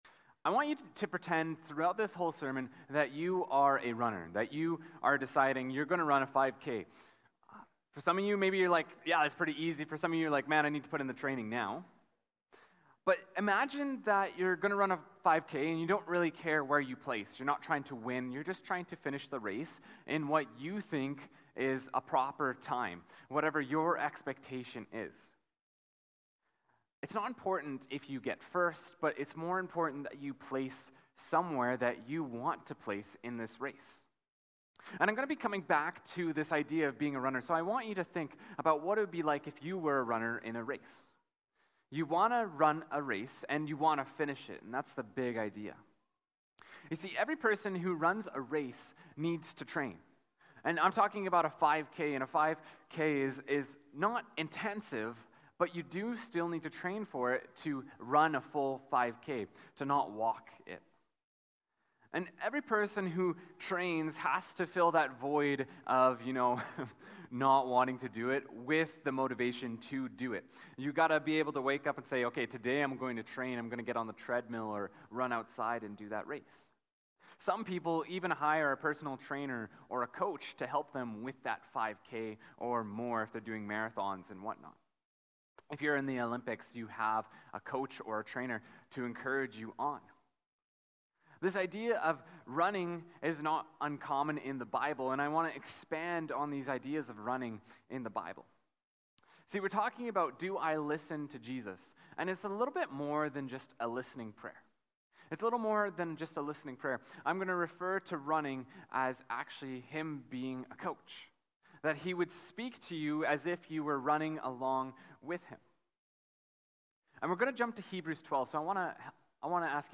Sermon Notes Latest Sermons The People of Jesus, The Chosen on May 2, 2024 The People of Jesus, A Royal Priesthood on April 28, 2024 The People of Jesus; Salt & Light on April 21, 2024